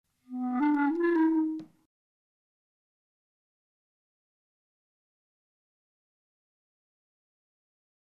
•  blurred, afterthought